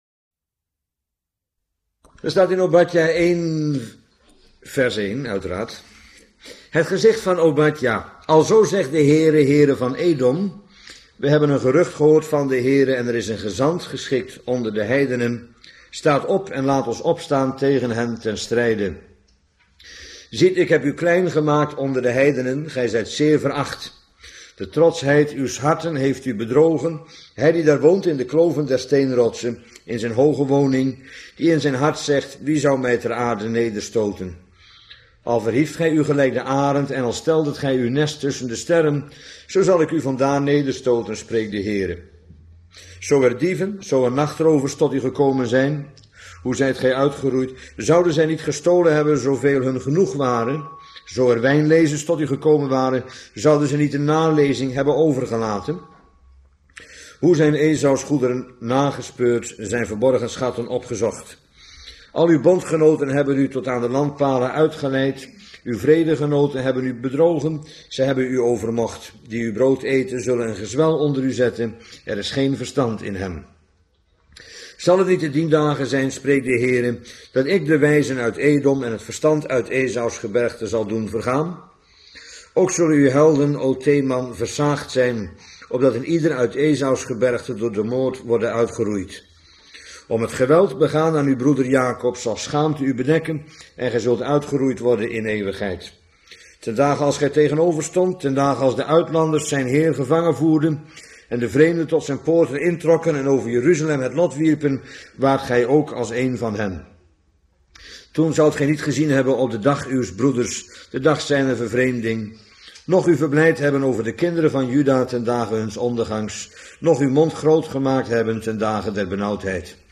Bijbelstudie lezingen